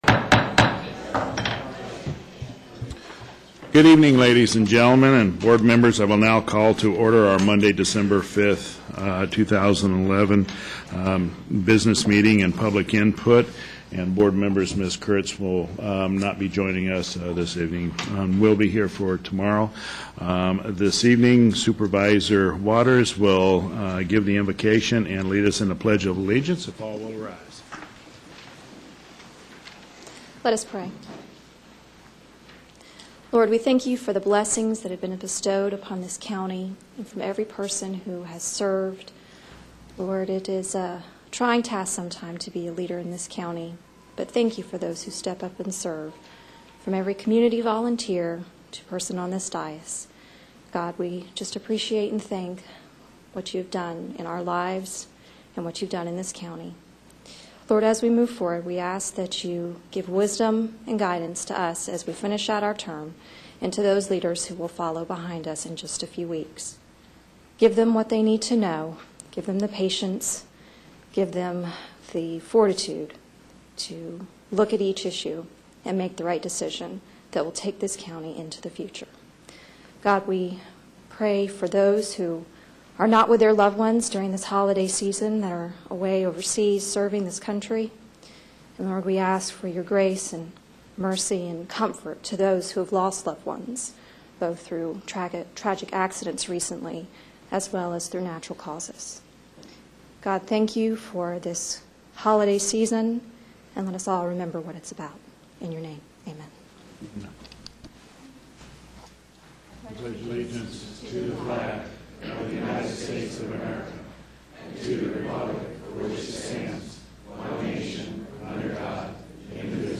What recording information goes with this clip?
Board of Supervisors General Business & Public Input - 12/05/11 - Dec 05, 2011